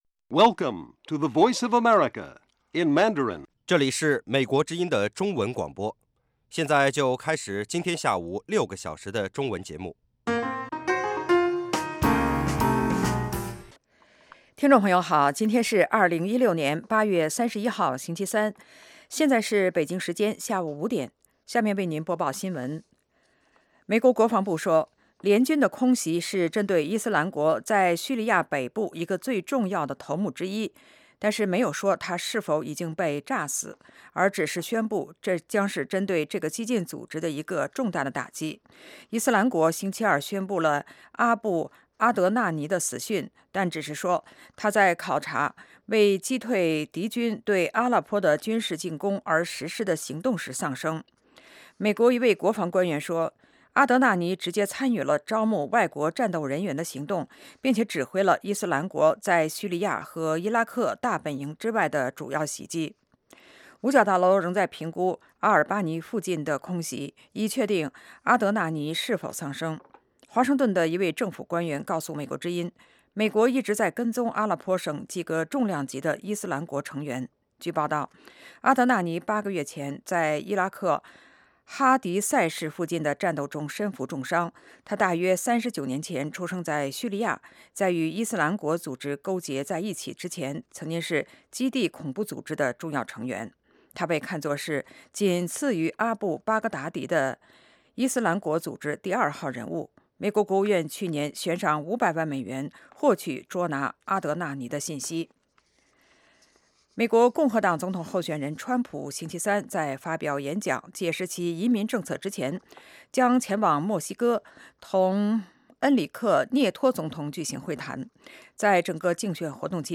北京时间下午5-6点广播节目。 内容包括国际新闻和美语训练班（学个词，美国习惯用语，美语怎么说，英语三级跳，礼节美语以及体育美语）